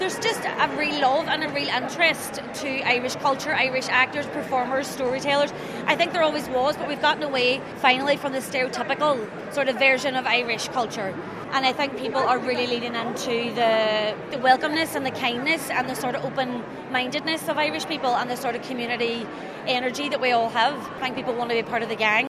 Derry Girls actress Jamie Lee O’Donnell stars in the film and says Irish actors are receiving lots of love on the global stage: